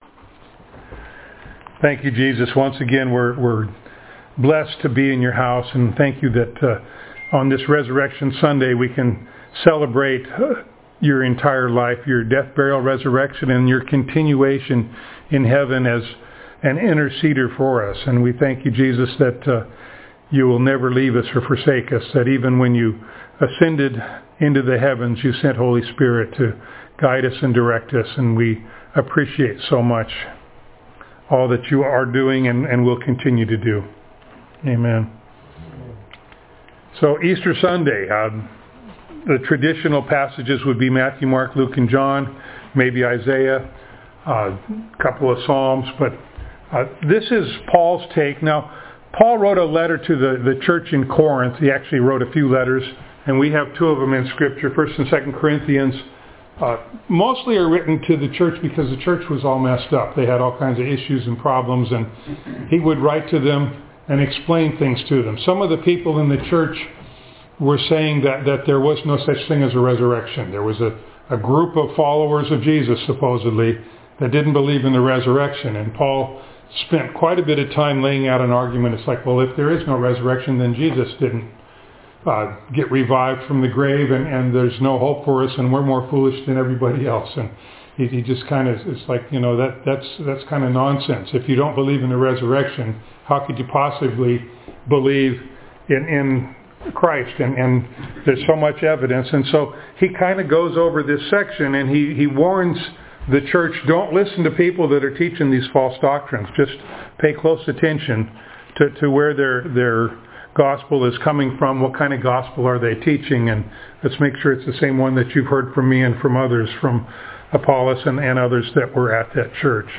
Easter Passage: 1 Corinthians 15:1-11, 15:35-58 Service Type: Sunday Morning Download Files Notes « God Knows He Will Keep You